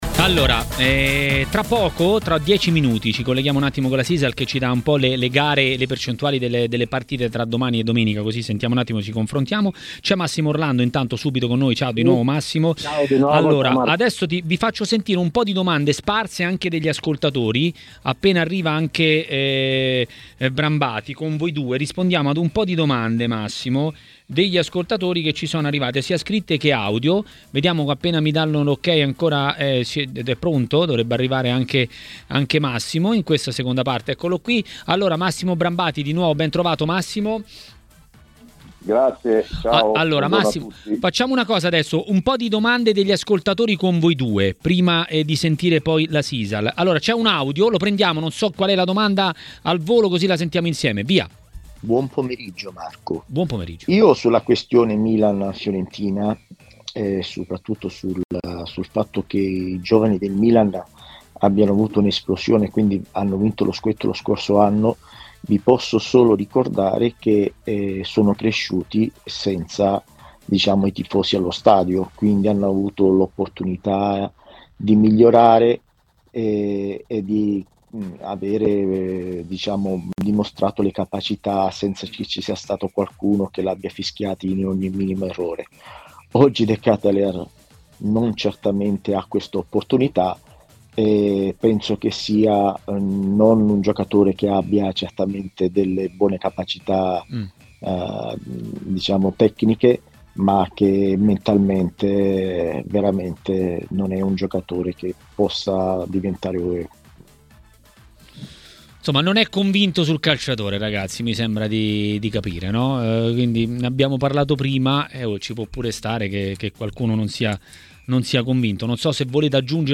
A commentare i temi del campionato a Maracanà, nel pomeriggio di TMW Radio